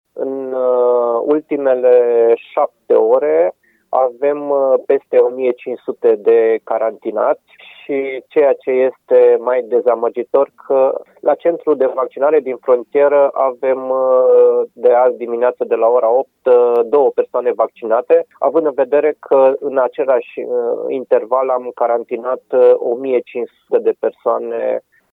În doar șapte ore, au fost fost plasați în carantină 1.500 dintre cei care au intrat în ţară pe la Nădlac II, dar oamenii aleg în continuare să nu se vaccineze, spune şeful Direcției de Sănătate Publică Arad, Horea Timiș.